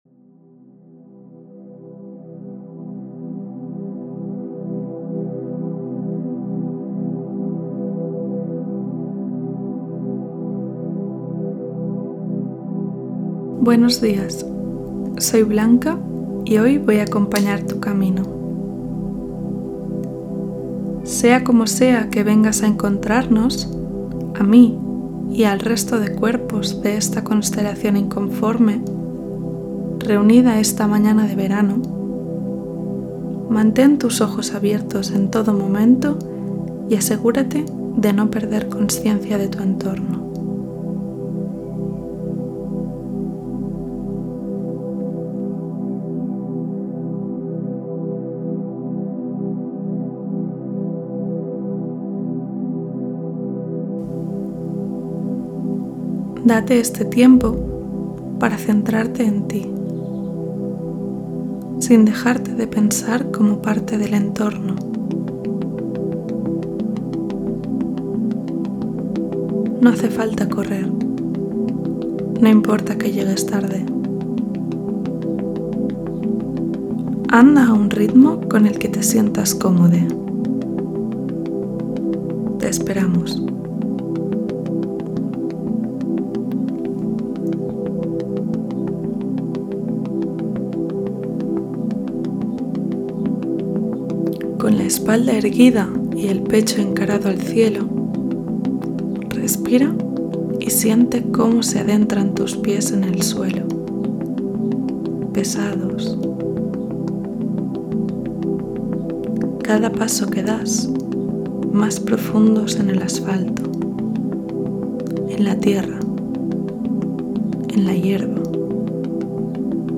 Aquest àudio té com a objectiu crear una predisposició física i mental, com un petit exercici de ritual meditatiu, abans de connectar amb l'obra de Miró.